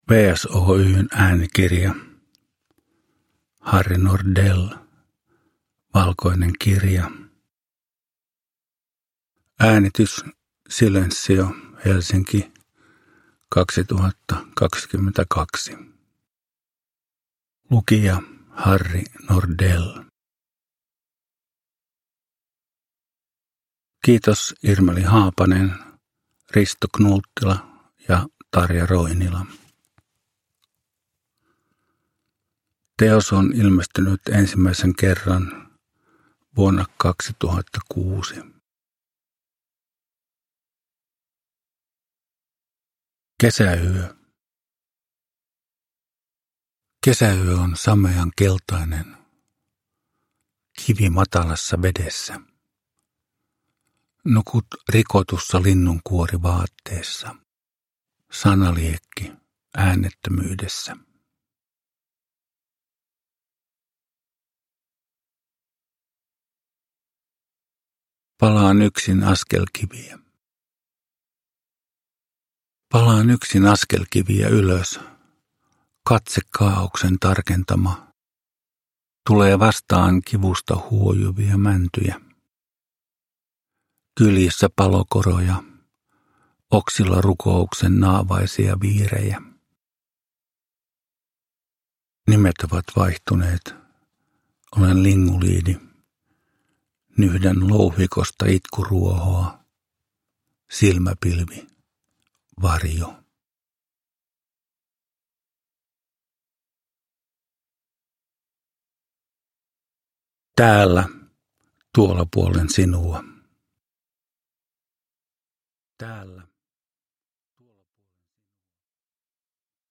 Valkoinen kirja – Ljudbok